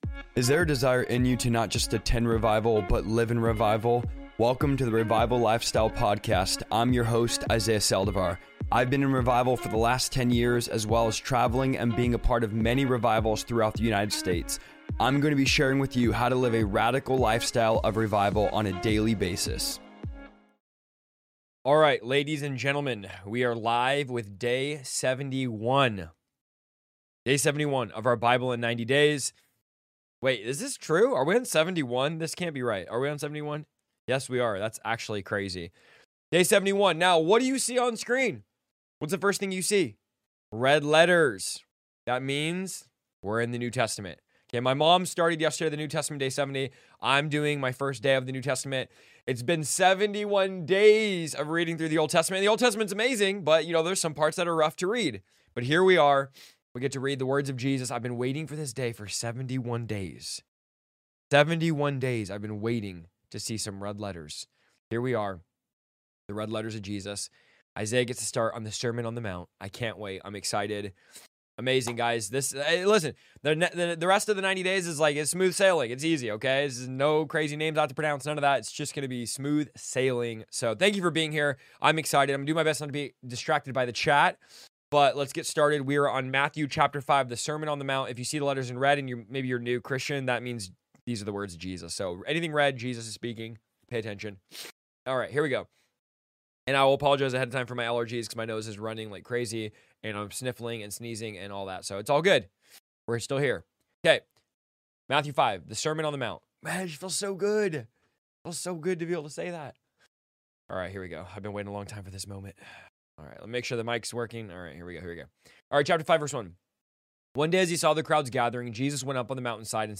Hey friends, I'm excited to share that I'll be going live for 90 days straight, reading through the entire Bible—from Genesis to Revelation—and I’d love for you to join me on this journey. Every day, we'll dive into Scripture together in a relaxed, interactive live session where you can ask questions, share thoughts, and explore the Bible in a way that feels personal and genuine.